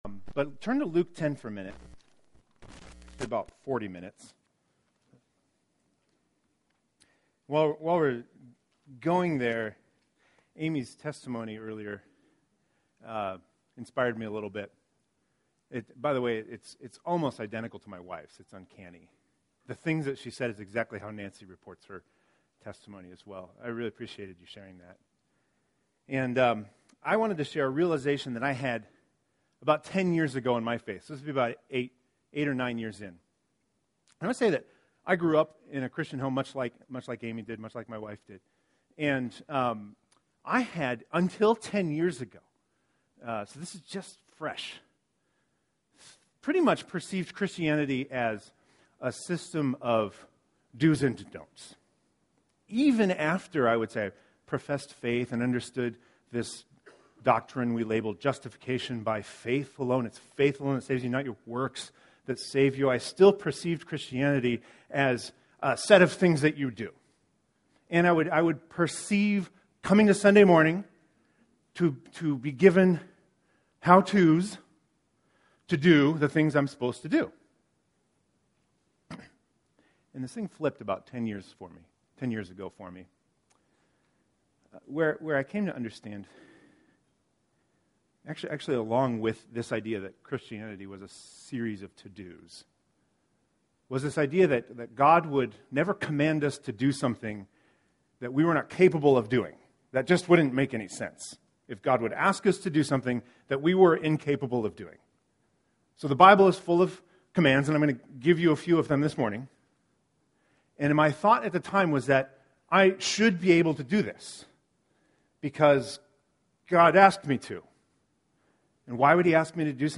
So why on earth should you be listening to this sermon?